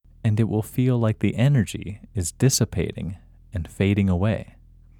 IN – Second Way – English Male 18